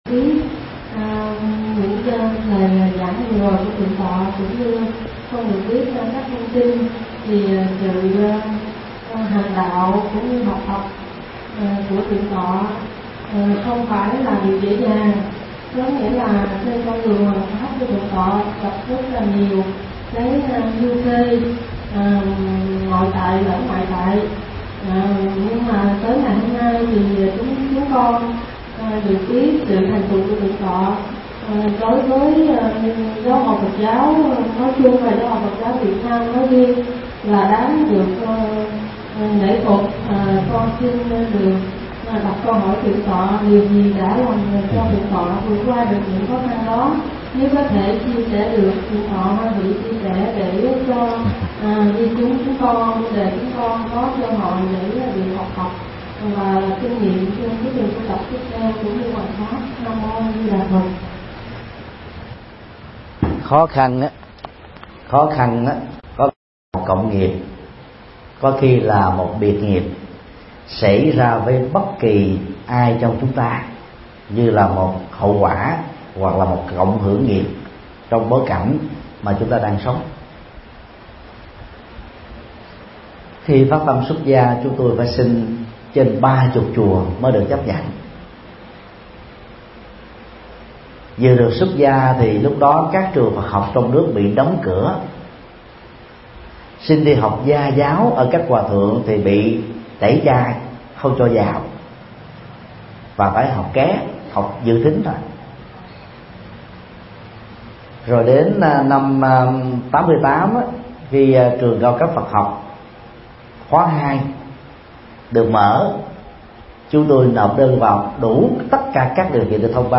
Vấn đáp: Kinh nghiệm trong tu tập – Thầy Thích Nhật Từ mp3